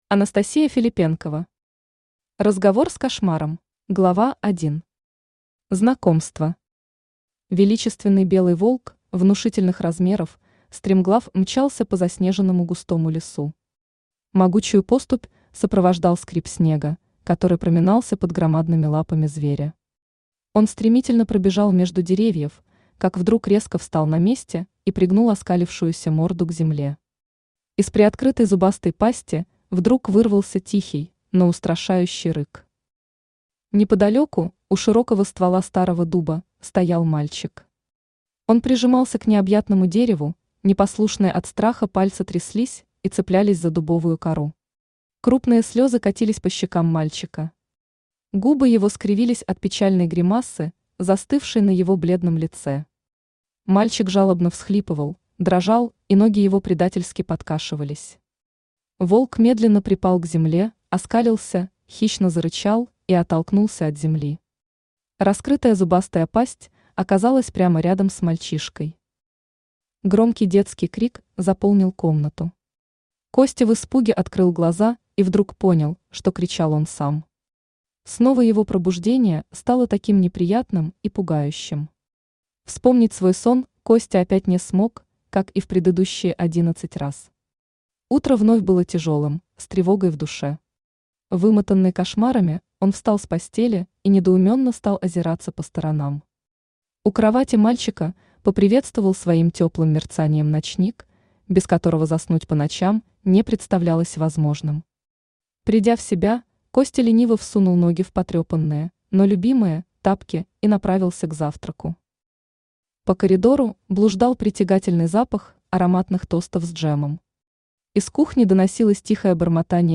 Аудиокнига Разговор с кошмаром | Библиотека аудиокниг
Читает аудиокнигу Авточтец ЛитРес.